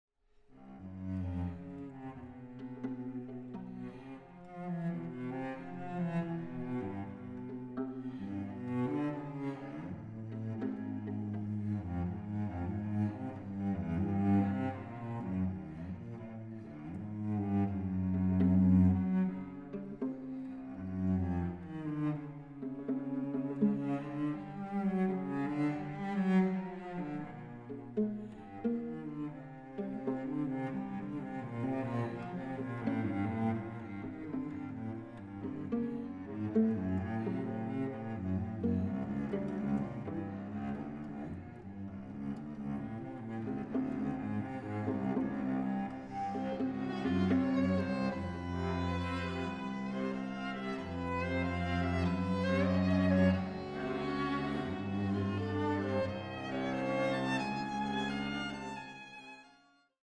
Recording: Festeburgkirche Frankfurt, 2024